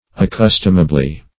Search Result for " accustomably" : The Collaborative International Dictionary of English v.0.48: Accustomably \Ac*cus"tom*a*bly\, adv. According to custom; ordinarily; customarily.